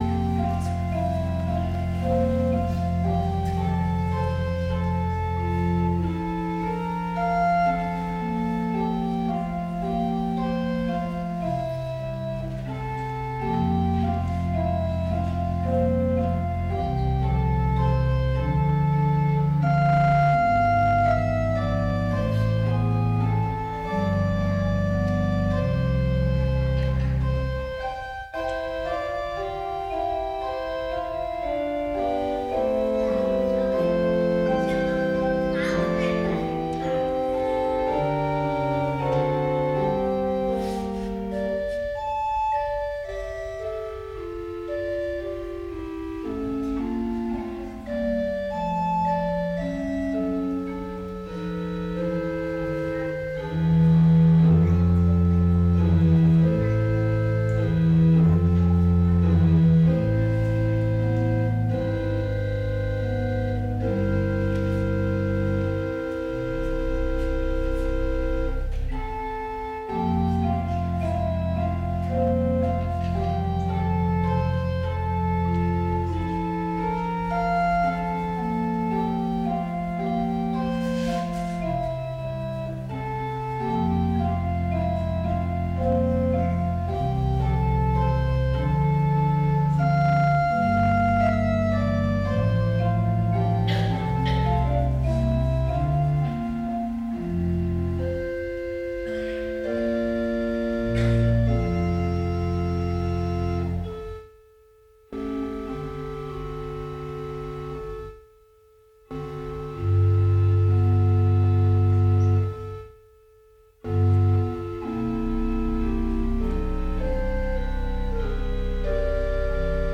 Gottesdienst - 28.12.2025 ~ Peter und Paul Gottesdienst-Podcast Podcast